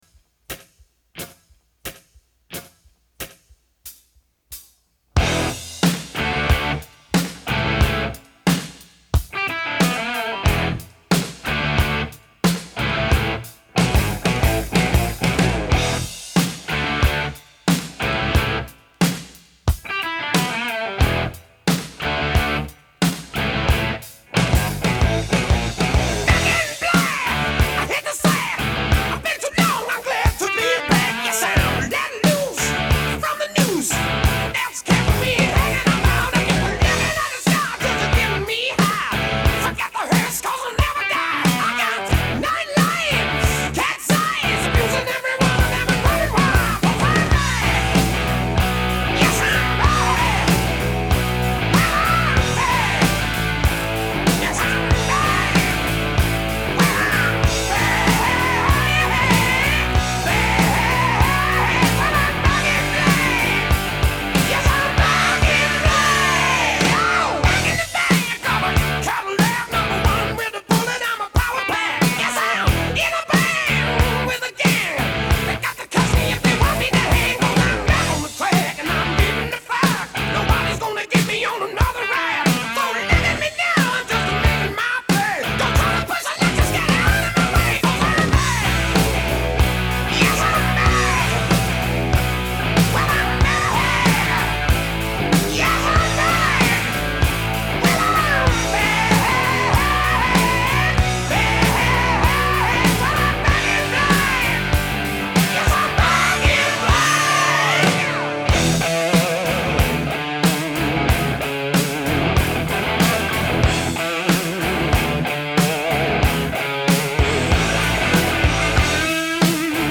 Rock 80er